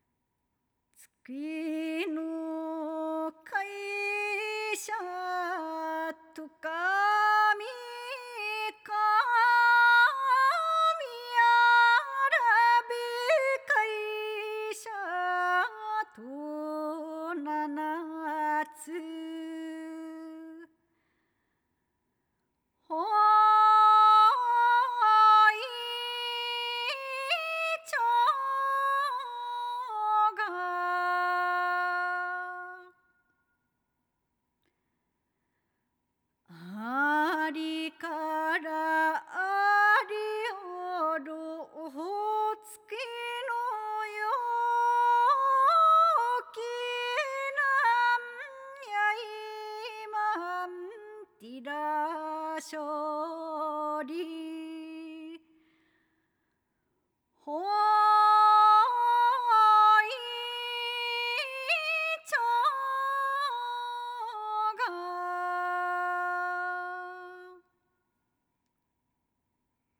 アカペラverはこちらから
③月ぬ美しゃ（アカペラ）.wav